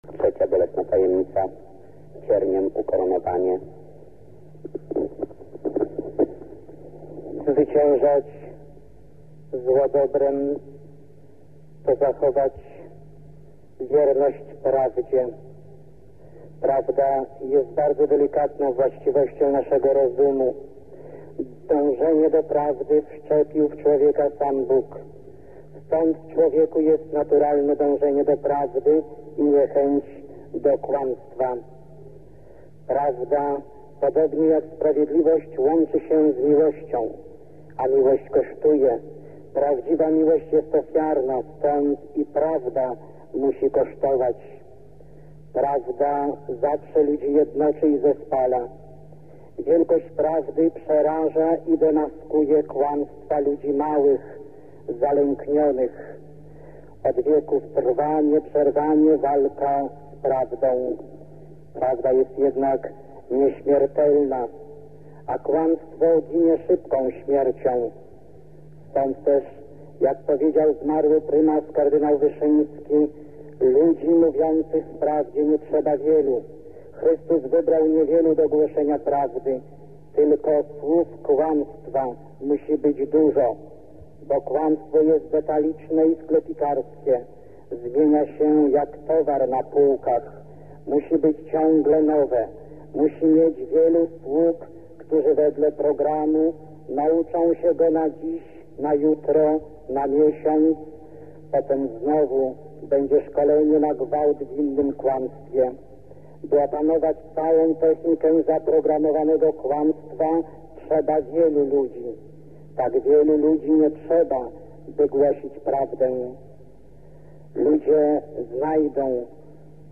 19 października 1984 o godz. 18, w bydgoskim kościele Braci Męczenników ks. Jerzy Popiełuszko odprawił nabożeństwo różańcowe.
Archiwalne nagranie z mową ks. Jerzego